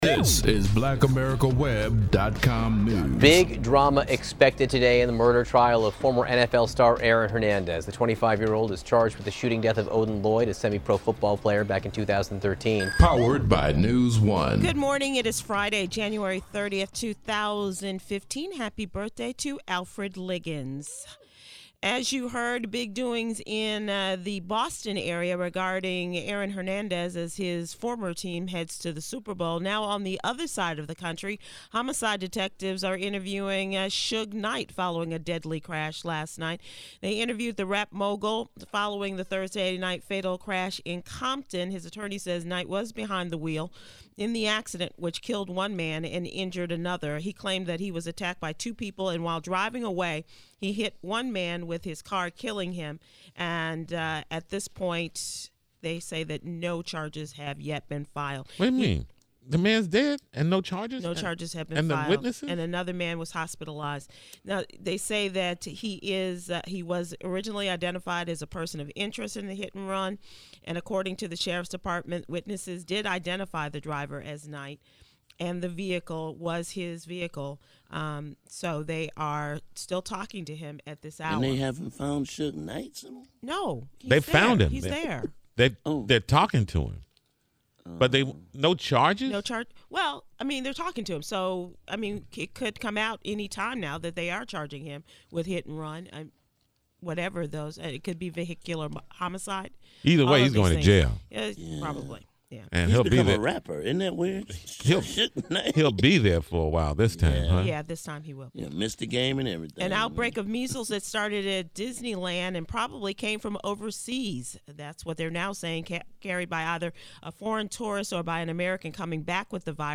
1/30/15- Sybil Wilkes discusses the morning’s news and celebrity headlines on the TJMS including, Suge Knight’s fatal hit and run, Richard Sherman’s early exit from the Super Bowl, the outbreak of the measles and the rejection of the General Robert Lee’s celebratory day on MLK’s birthday.